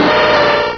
Cri de Mackogneur dans Pokémon Rubis et Saphir.